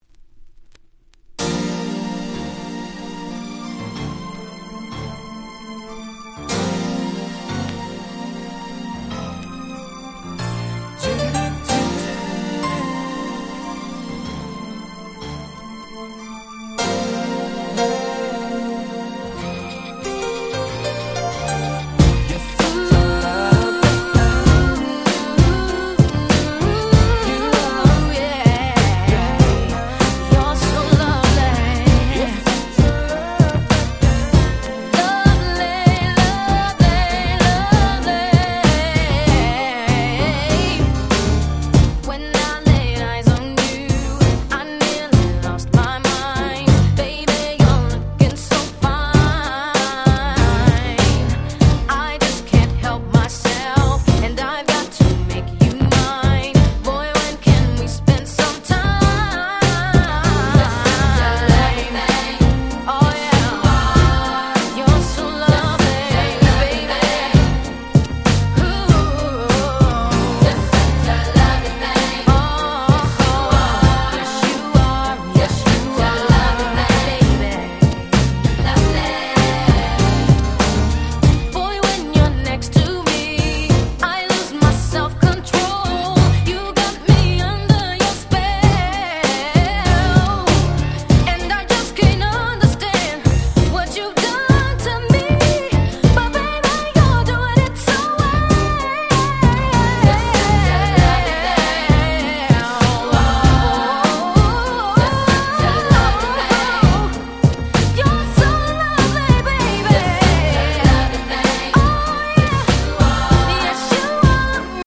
94' Super Nice R&B !!